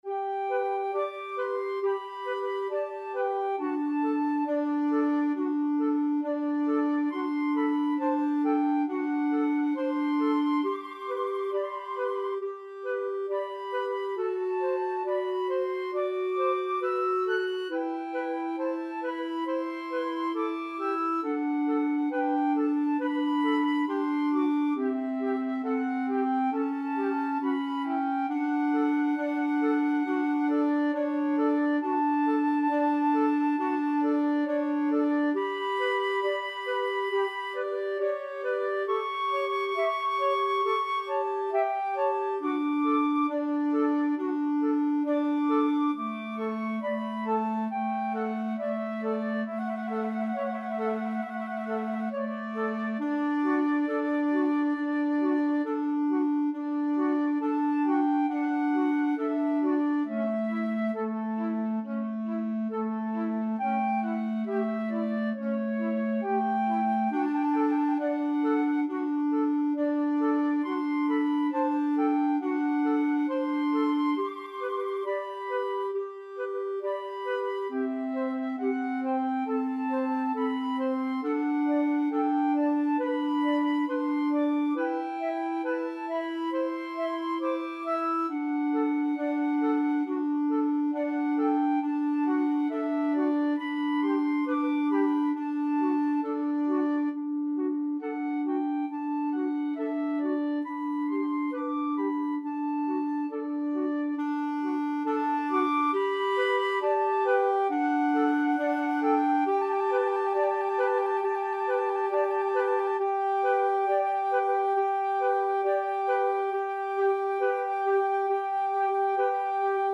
Flute 1Flute 2Clarinet
4/4 (View more 4/4 Music)
= 34 Grave
Classical (View more Classical 2-Flutes-Clarinet Music)